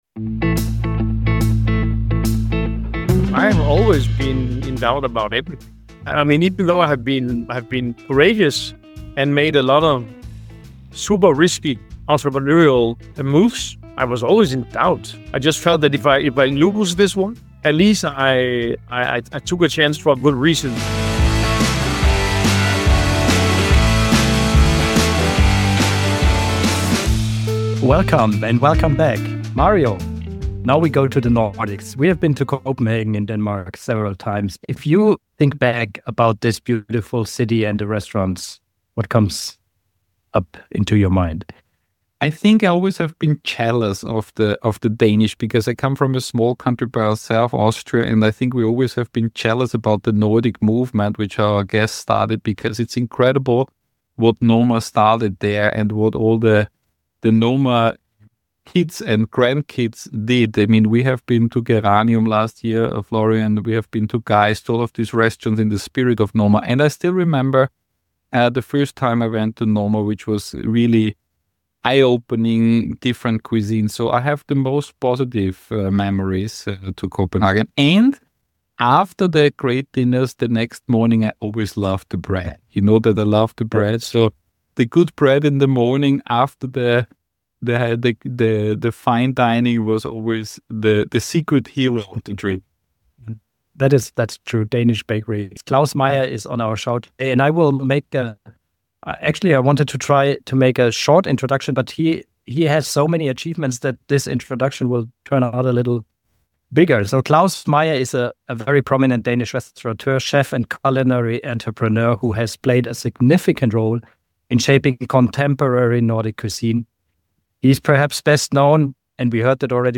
This episode’s guest is literally a person who mastered the concept of “from doubt to drive” through his career and life: We are happy to have Claus Meyer, serial culinary entrepreneur and co-founder of NOMA, on our show.